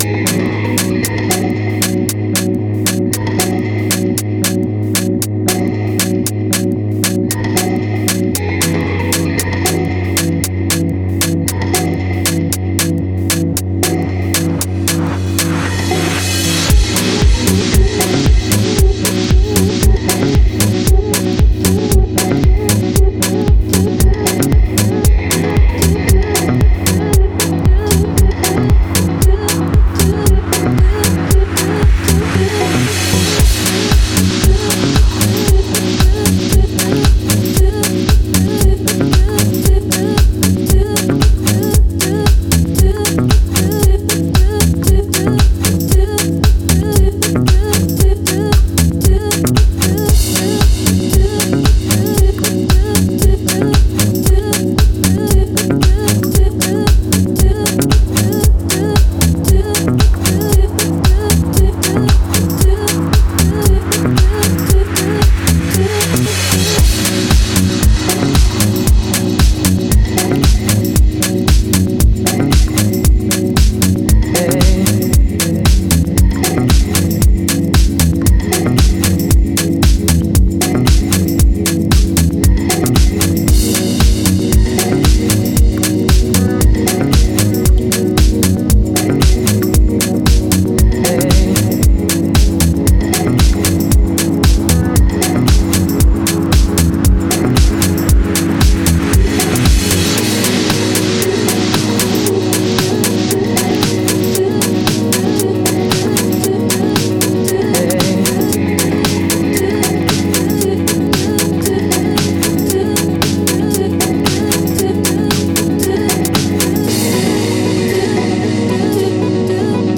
Genre: Deep House/Tech House.